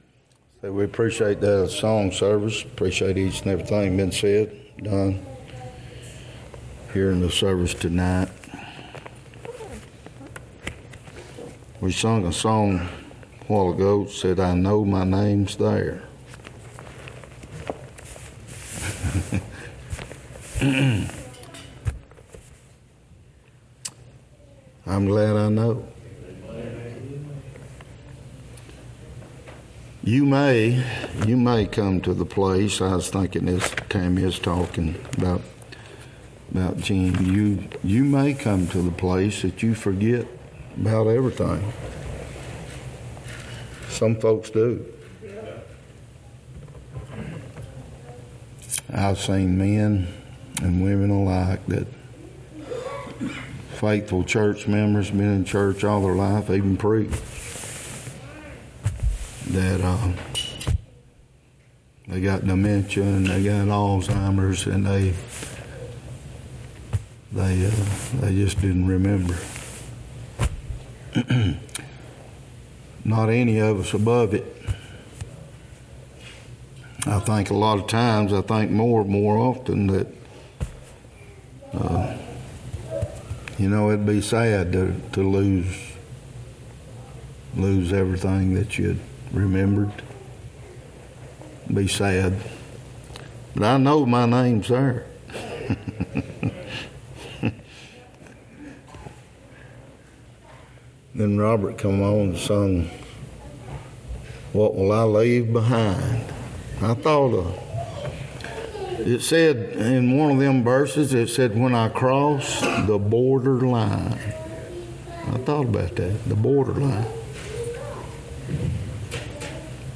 2 Peter 1:1-21 Service Type: Wednesday night Topics